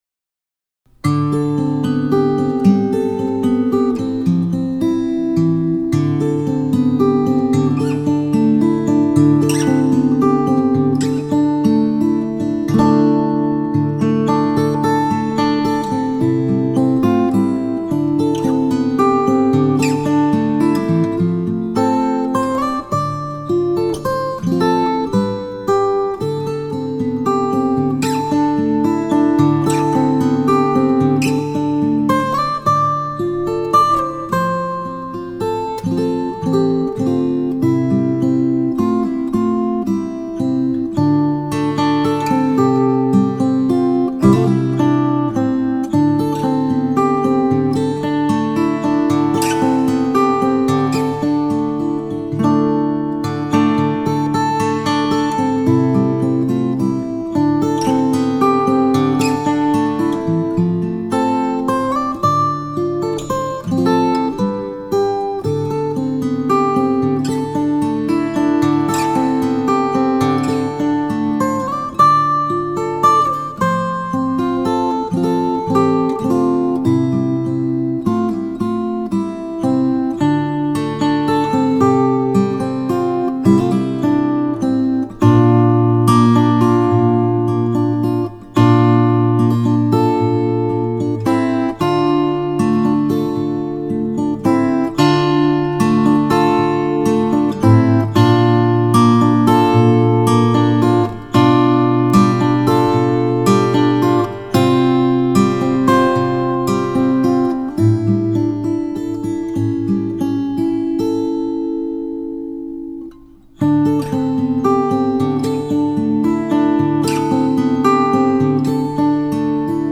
L-00 typeB カポ５ スカボロ ６トラックの音 MP3